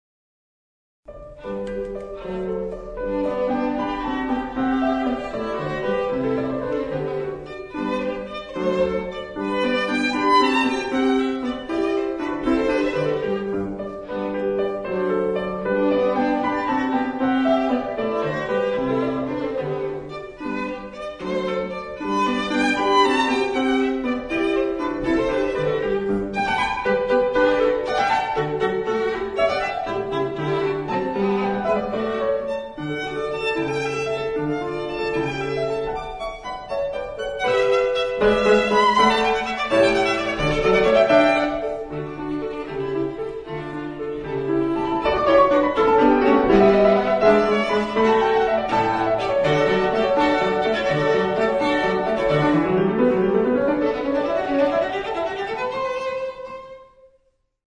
Violon
Allegro